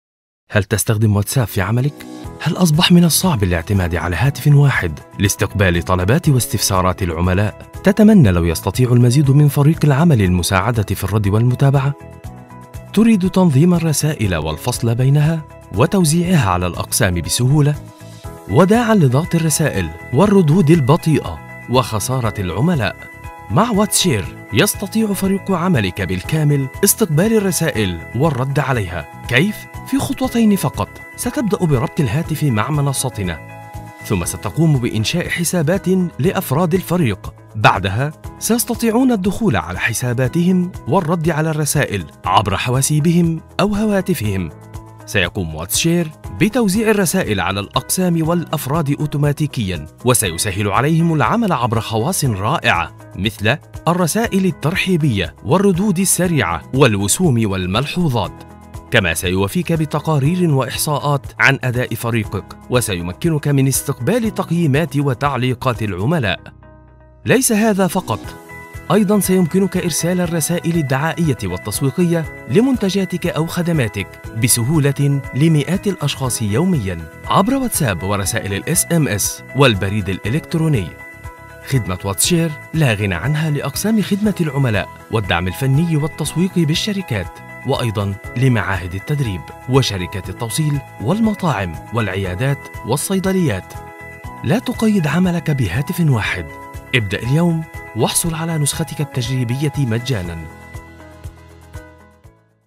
Professional Arabic Standard Classic Gulf accent.